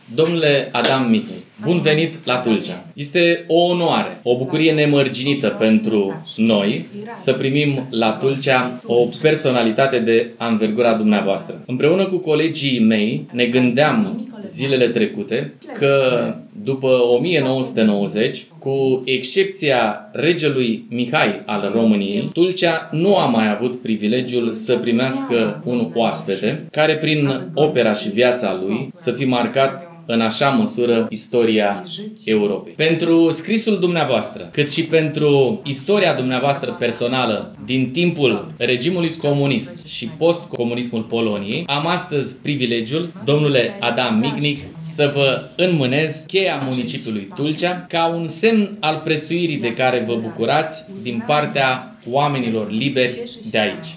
Primarul Ștefan Ilie a punctat, în cuvântul său, că distincția acordată lui Adam Michnik este un gest de recunoaștere a curajului și a contribuției sale la apărarea libertății și democrației în Europa.